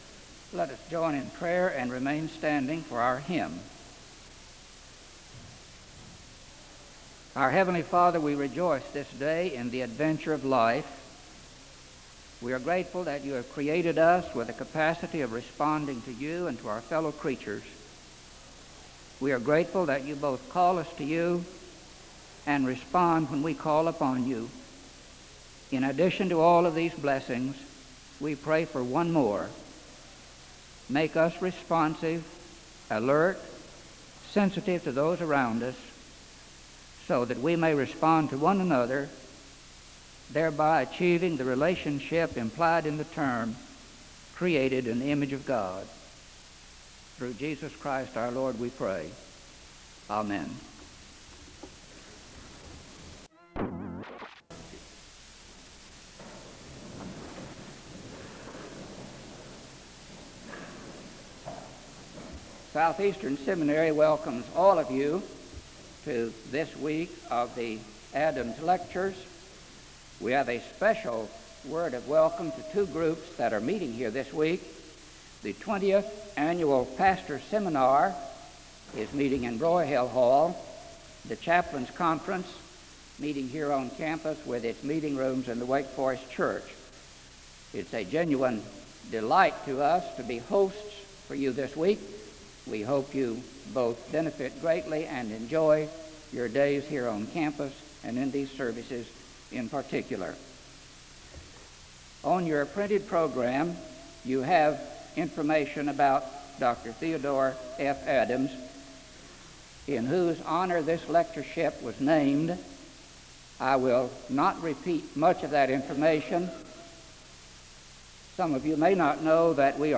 The service begins with a word of prayer (00:00-01:00).